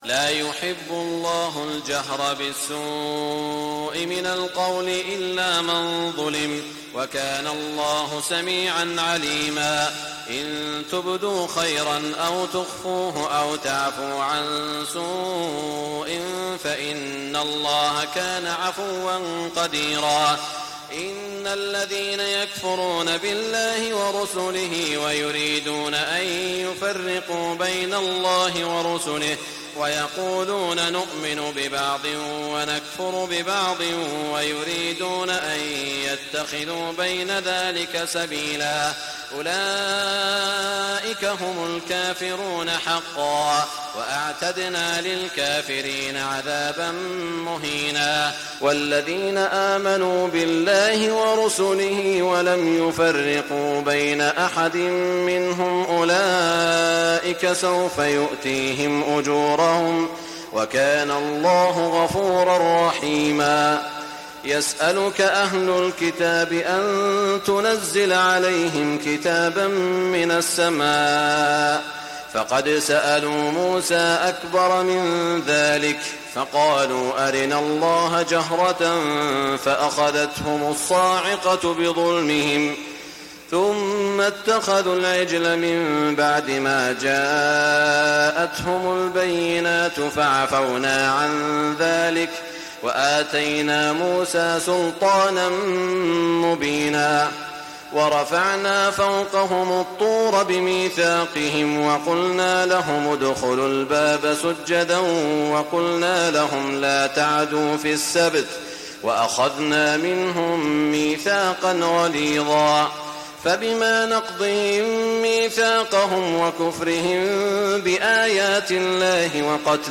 تهجد ليلة 26 رمضان 1420هـ من سورتي النساء (148-176) و المائدة (1-40) Tahajjud 26 st night Ramadan 1420H from Surah An-Nisaa and AlMa'idah > تراويح الحرم المكي عام 1420 🕋 > التراويح - تلاوات الحرمين